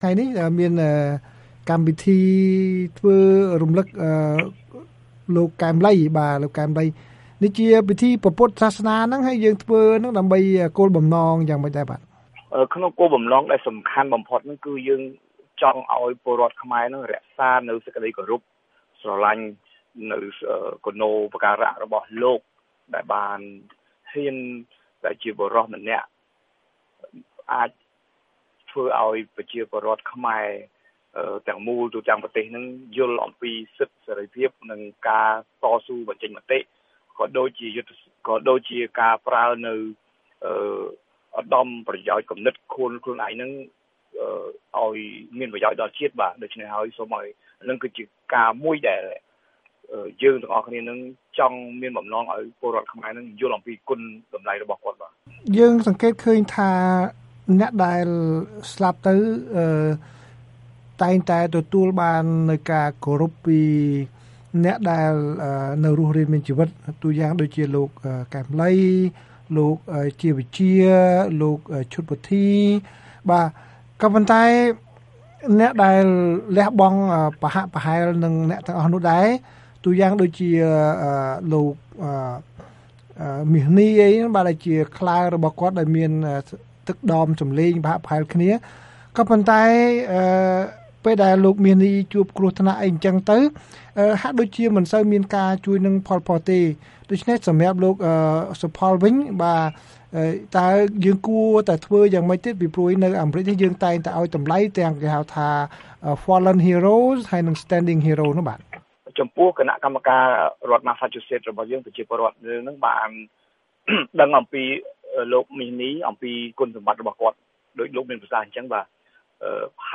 បទសម្ភាសន៍ VOA៖ ខ្មែរ-អាមេរិកាំងនៅរដ្ឋម៉ាស្សាឈូសិតរំឭកខួបទី៣នៃមរណភាពលោក កែម ឡី